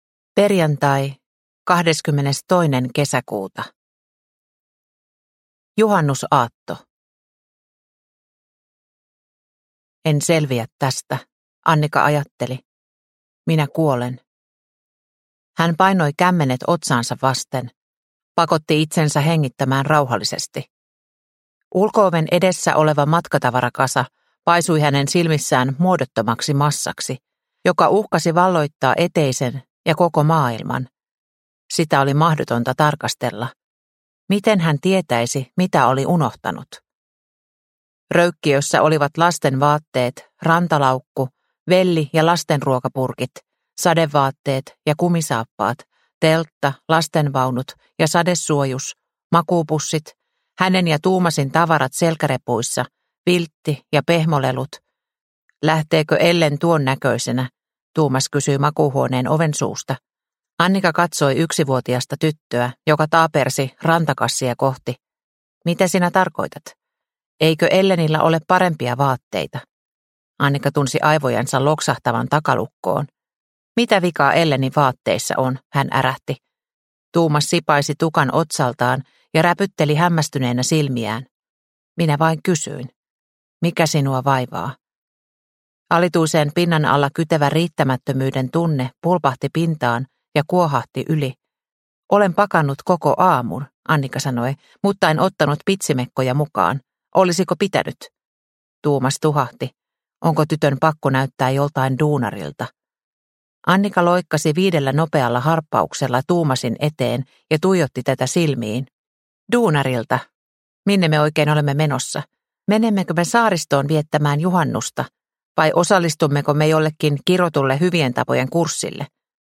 Prime time – Ljudbok – Laddas ner